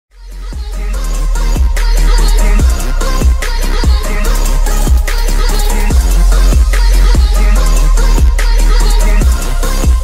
twitch-follower-sub-donate-sound-effect-alert-1-mp3cut.mp3